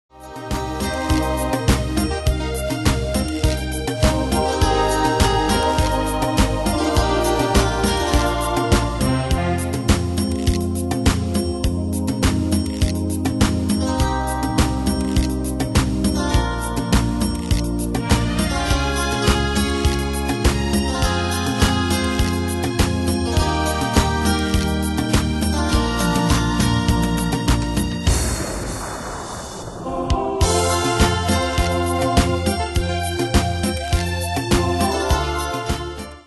Demos Midi Audio
Style: Dance Année/Year: 2002 Tempo: 102 Durée/Time: 3.44
Danse/Dance: Dance Cat Id.
Pro Backing Tracks